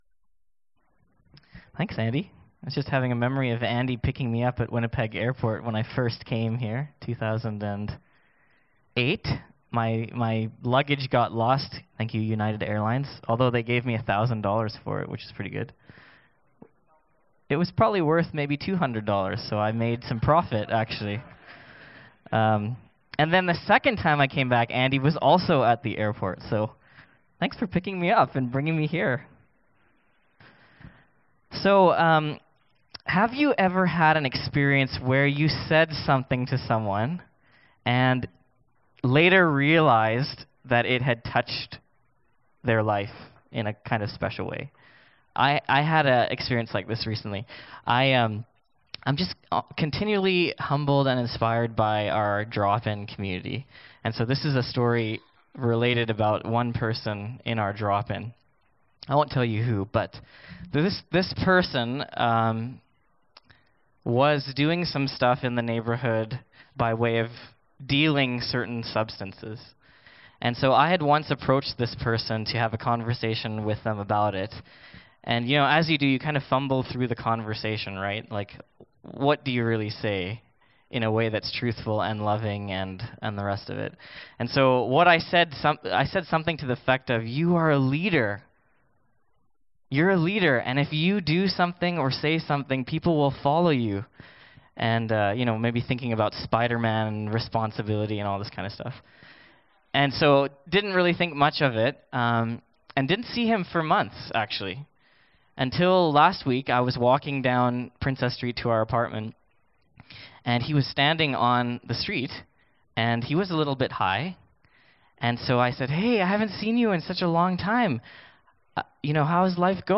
19 Service Type: Downstairs Gathering Bible Text